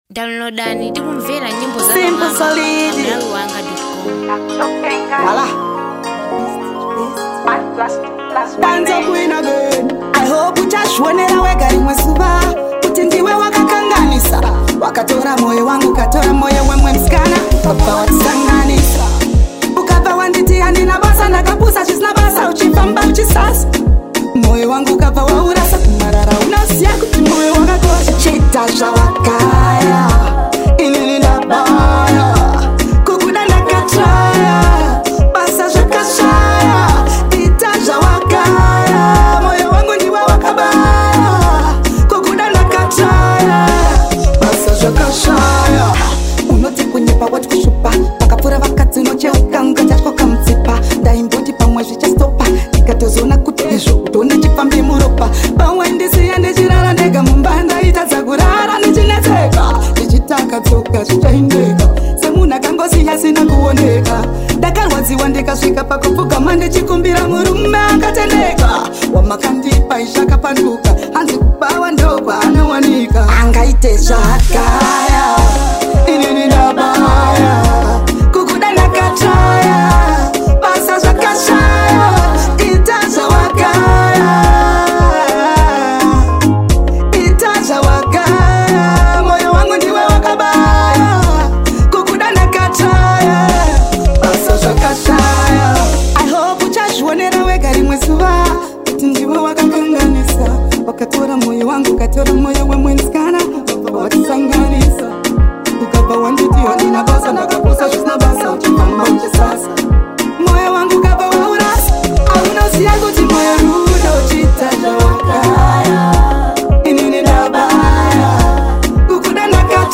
Zimdancehall Female Artiste